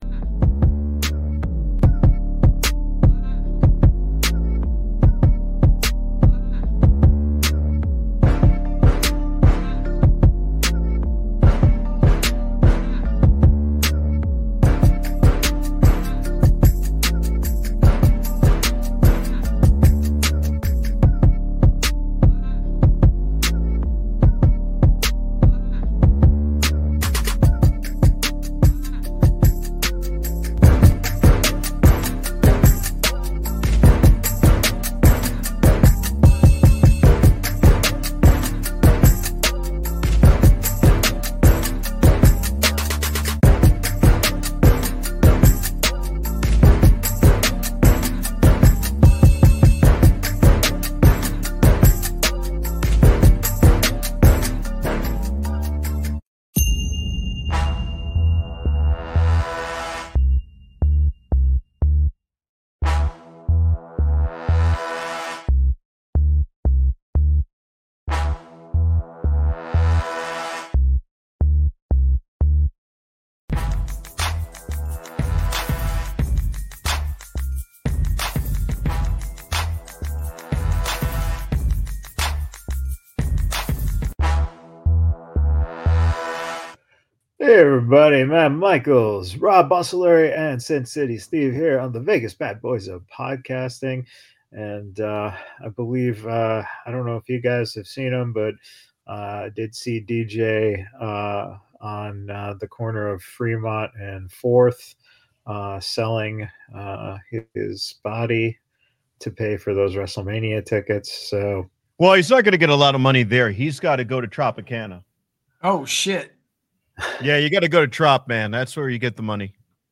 Join the Badboyz live as they talk about this past week in pro wrestling